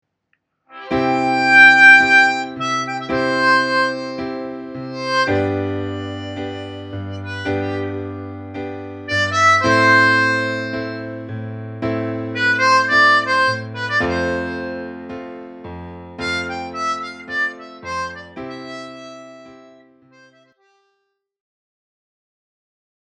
• перескакивать через одну и более нот в гамме (тут отлично может даже подойти использование упражнения leapfrog).
Pereskoki-cherez-notyi.mp3